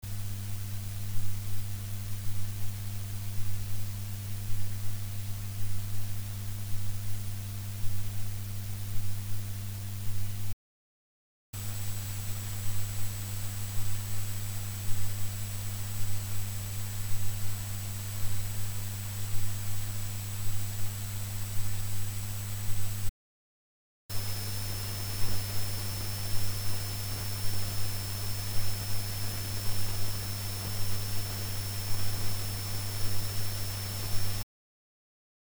PC側で48dB増幅して，ノイズを聴きやすく拡大したもの．
掲載したファイルでは24Vで7870Hz，48Vで5300Hzと10630Hzに出ている．
24Vは48Vよりノイズ音の大きさが小さい(半分以下)になっている．
また，このピークは時間の経過と共にゆっくりと音程が変化する．
phantom_noise_catalog.mp3